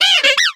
Cri de Chaglam dans Pokémon X et Y.